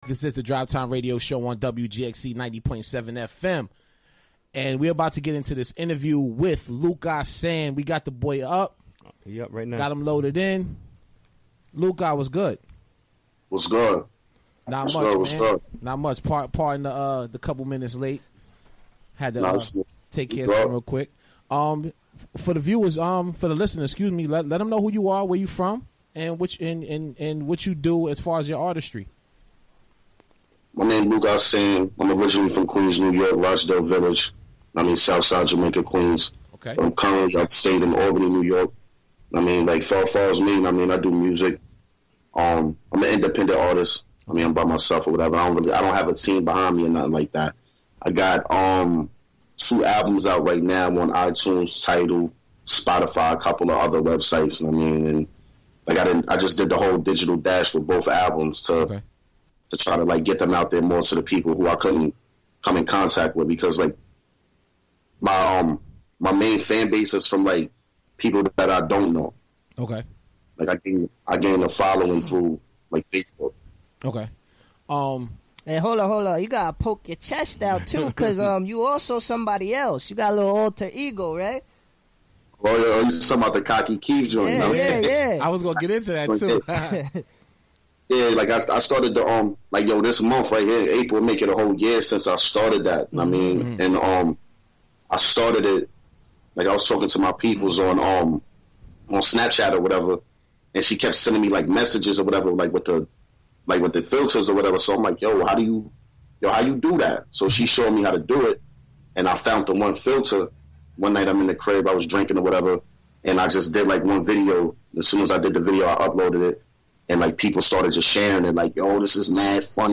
Recorded live, via Skype during the WGXC Afternoon Show Wed., Apr. 12, 2017.